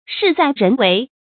shì zài rén wéi
事在人为发音
成语正音为，不能读作“wèi”。